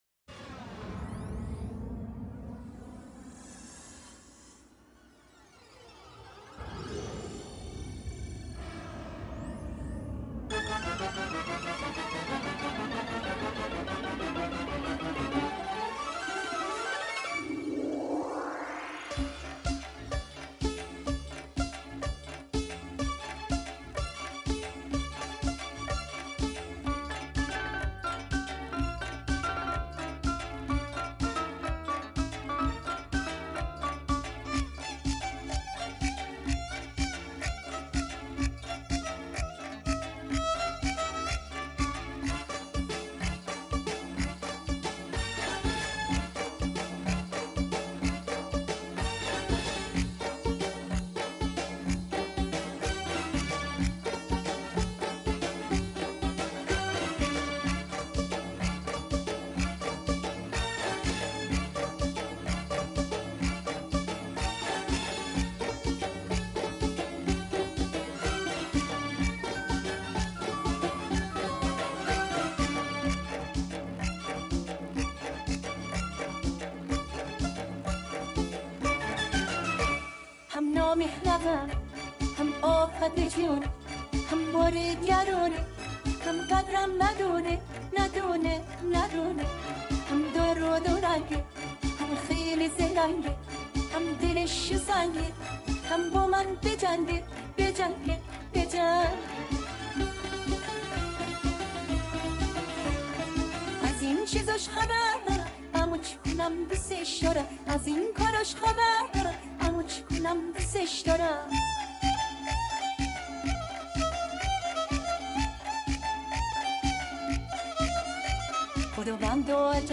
اهنگ شاد ایرانی
اهنگ محلی